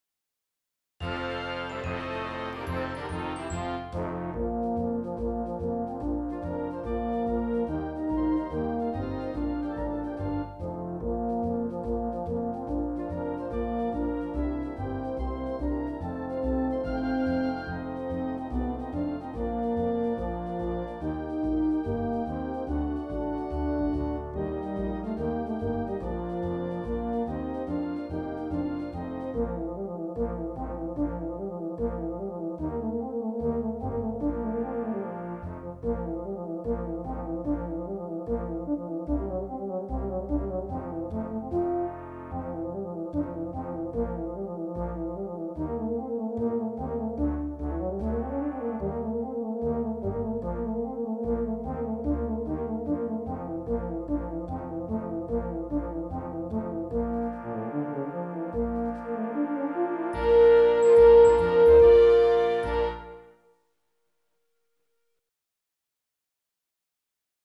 Voicing: TptEu Solo w/ Band